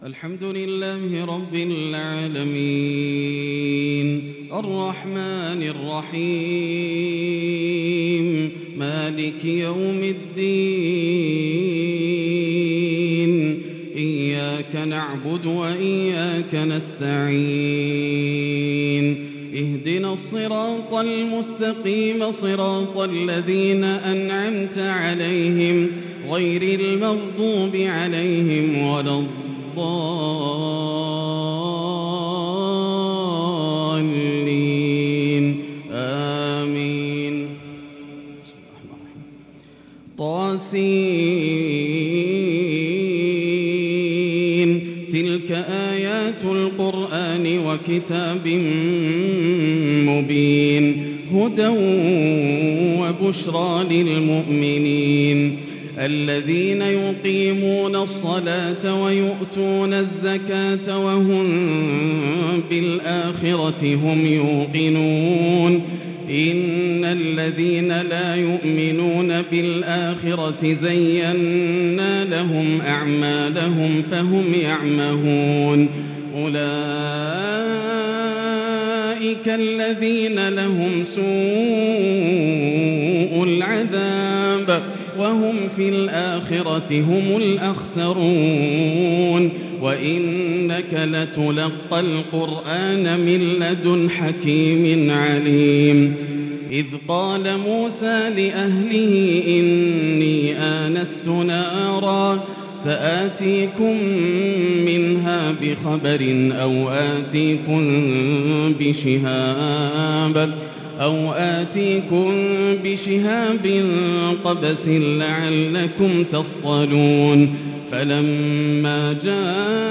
صلاة التراويح l ليلة 23 رمضان 1442 l من سورة النمل (1-58) taraweeh prayer The 23rd night of Ramadan 1442H | from surah An-Naml > تراويح الحرم المكي عام 1442 🕋 > التراويح - تلاوات الحرمين